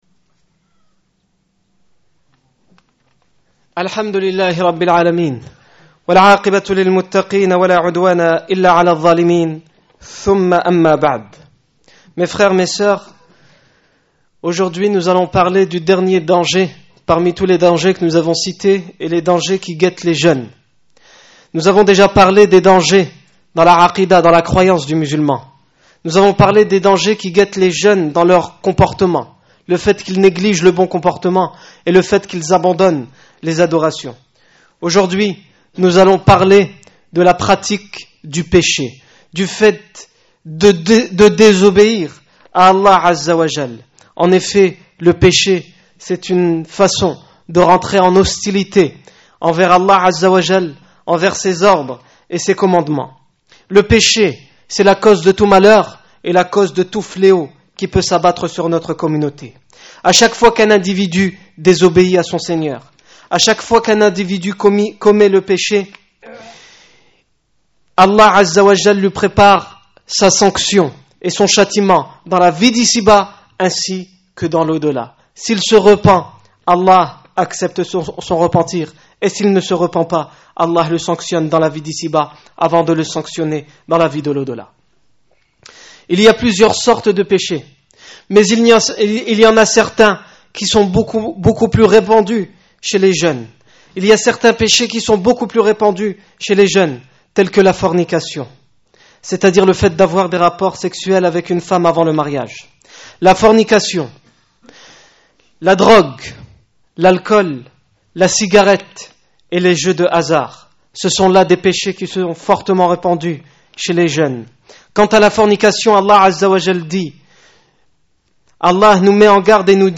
Discours du 7 novembre 2008
Accueil Discours du vendredi Discours du 7 novembre 2008 Le danger dans le comportement des jeunes